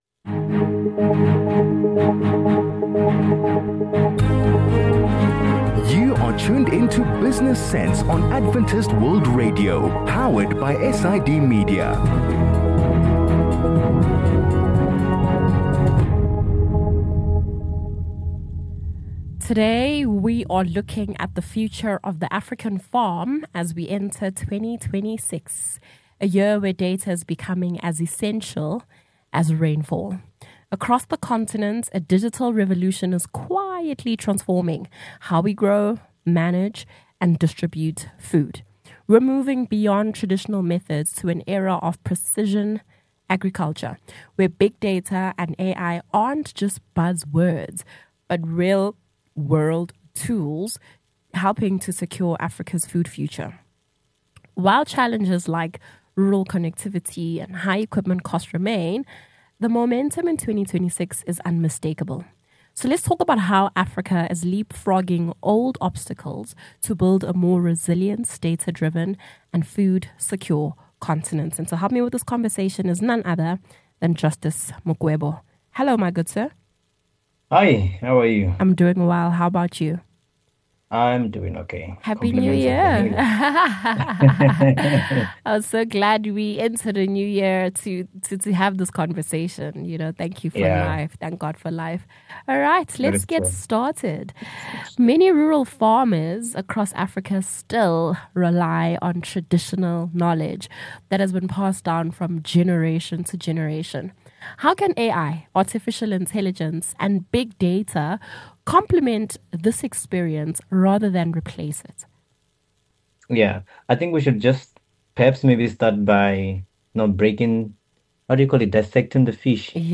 A conversation on how Africa is leapfrogging old obstacles to build a more resilient, data-driven, and food-secure continent.